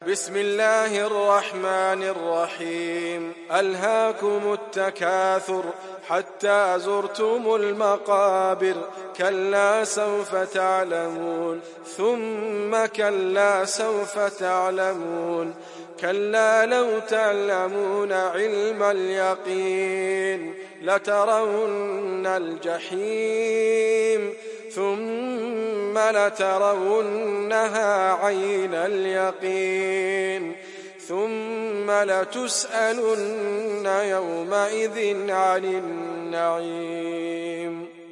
تحميل سورة التكاثر mp3 بصوت إدريس أبكر برواية حفص عن عاصم, تحميل استماع القرآن الكريم على الجوال mp3 كاملا بروابط مباشرة وسريعة